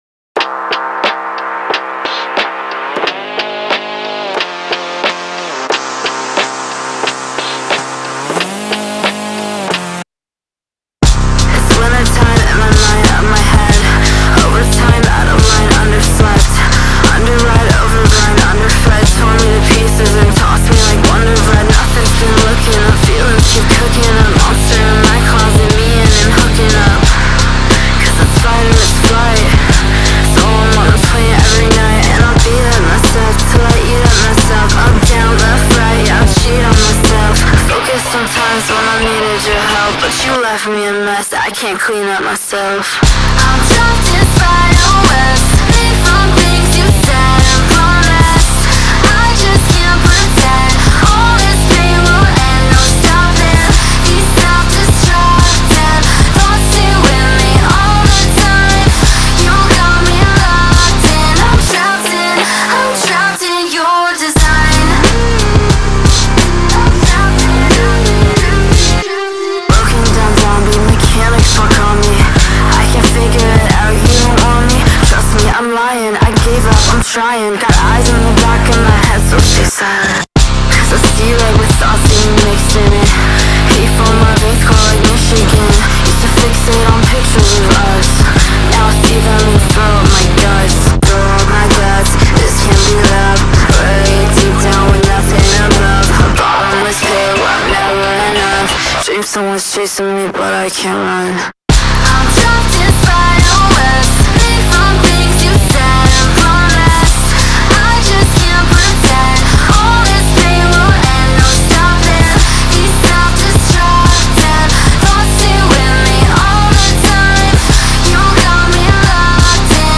BPM90-90
Audio QualityPerfect (High Quality)
Alternative Pop song for StepMania, ITGmania, Project Outfox
Full Length Song (not arcade length cut)